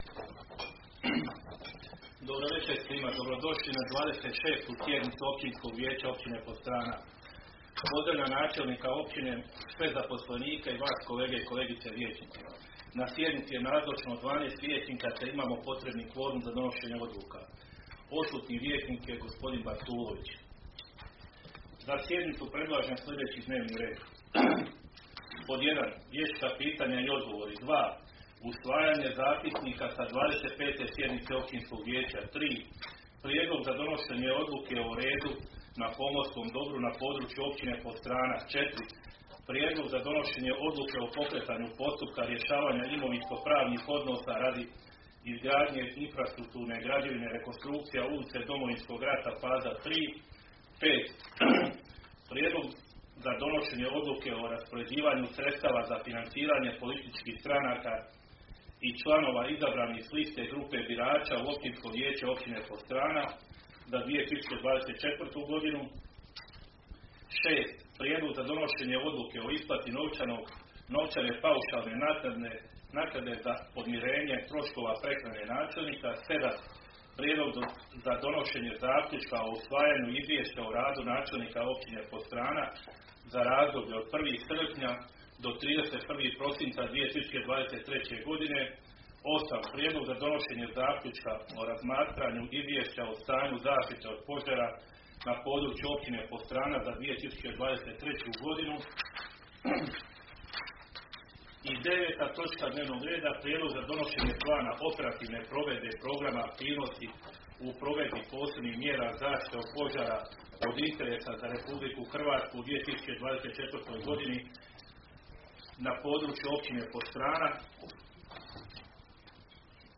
Sjednica će se održati dana 14. ožujka (četvrtak) 2024. godine u 19,00 sati u vijećnici Općine Podstrana.